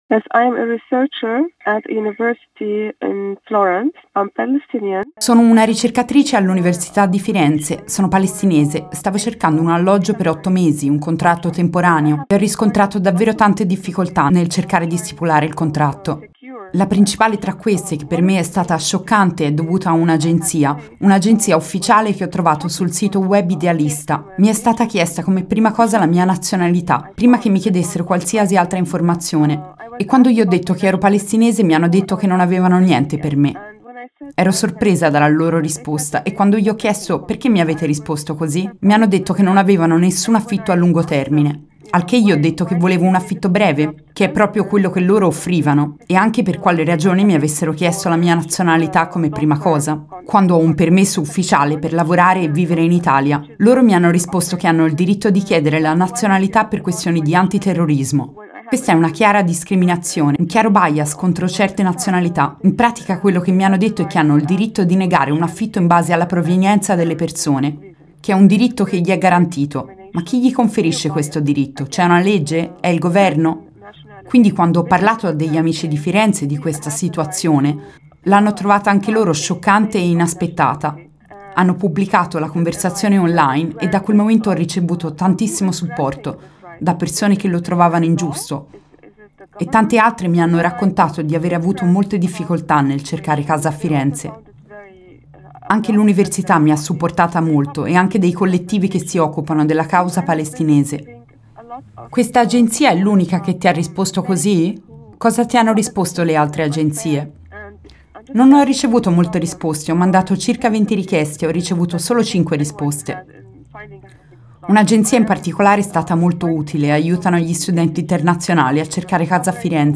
Affitto e discriminazioni. La testimonianza di una ricercatrice palestinese